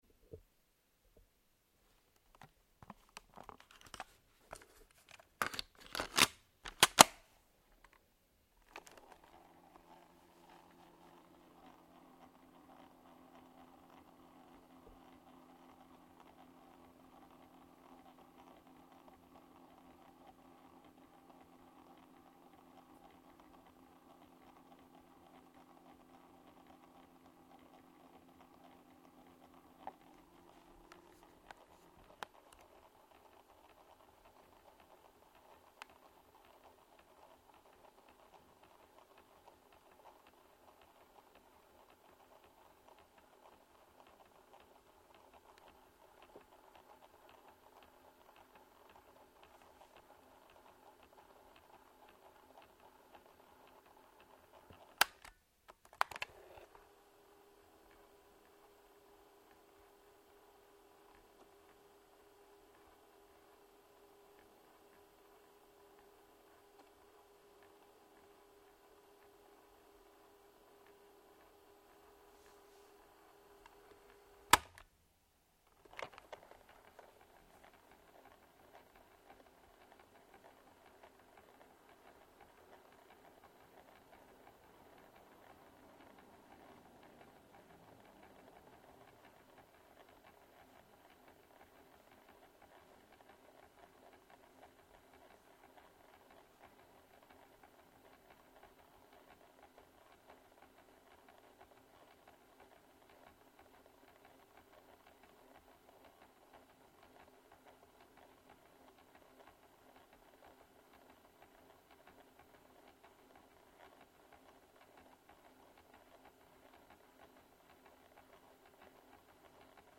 Sony Walkman
The sound of a Walkman recorded with zoom h5 and a contact microphone.